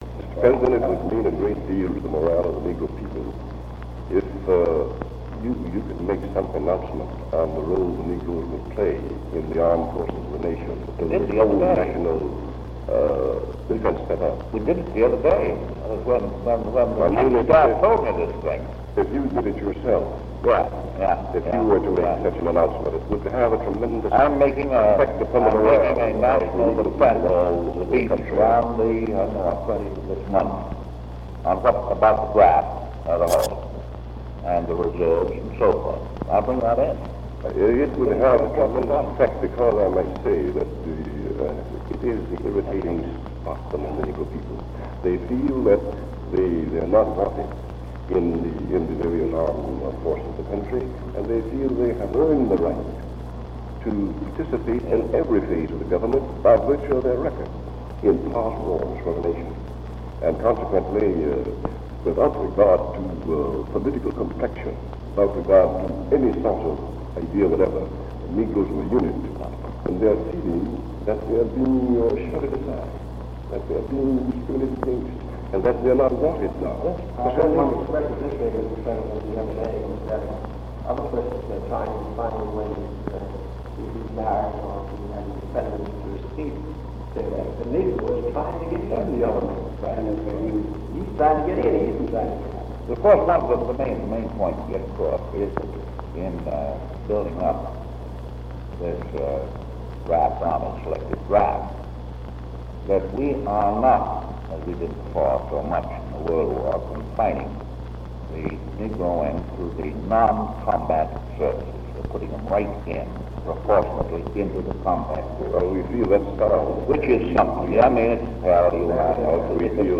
The Presidency / Featured Content 'The Right to Participate' 'The Right to Participate' Photo: National Archives and Records Administration Desegregation of the U.S. Armed Forces emerged as a civil rights issue as the United States responded to the expanding wars in Europe and Asia. This conversation between President Roosevelt and civil rights leaders highlighted the challenge of ending discrimination in the U.S. military.
Location: Executive Offices of the White House